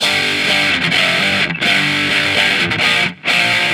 Guitar Licks 130BPM (6).wav